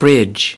1 bridge (n) /brɪdʒ/ Cây cầu